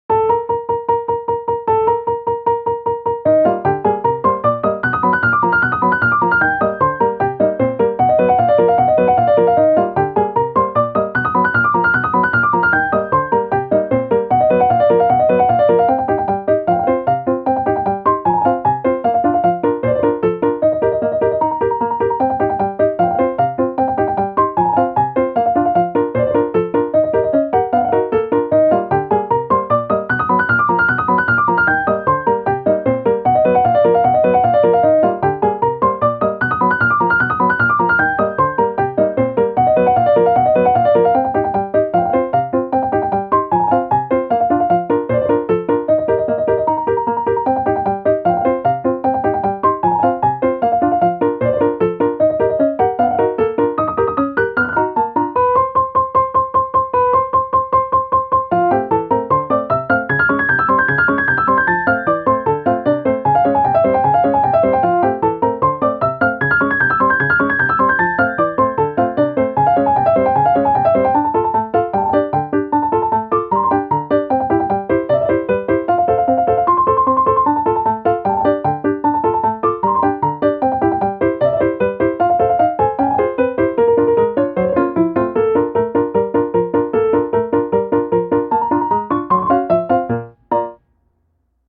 ogg(L) - かわいい 疾走 コミカル